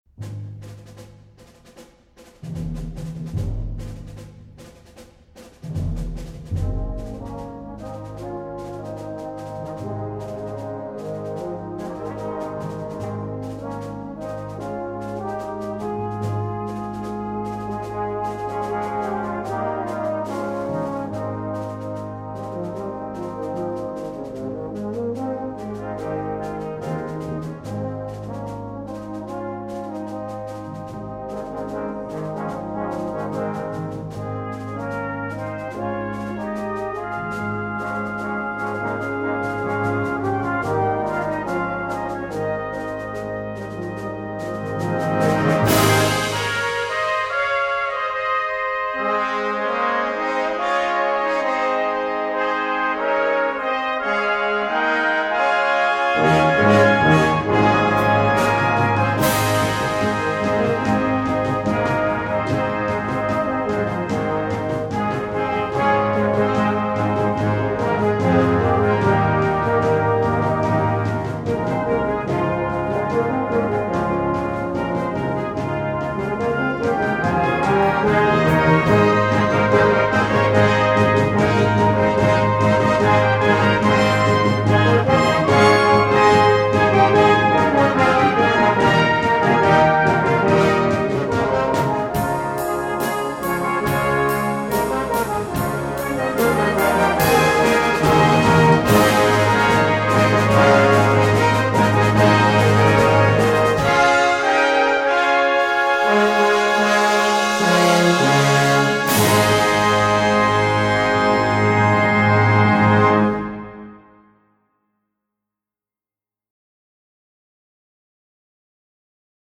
Composition Style: Fanfare